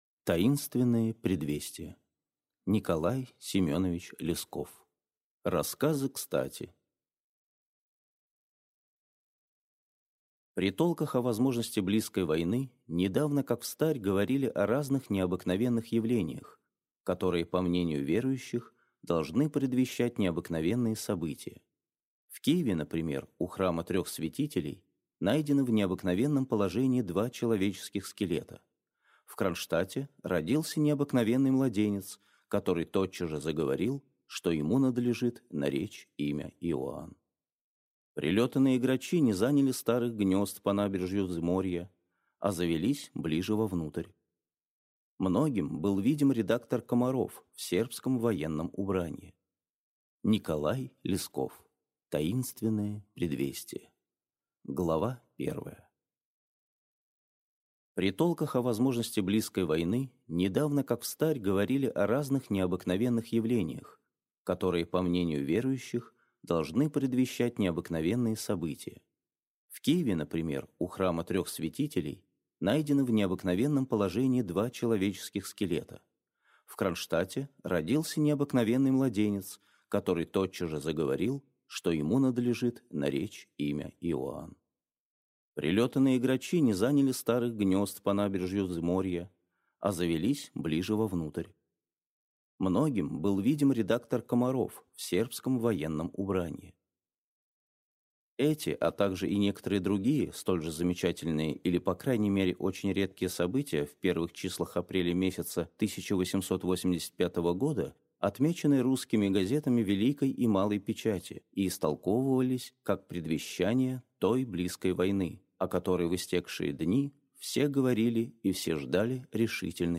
Аудиокнига Таинственные предвестия | Библиотека аудиокниг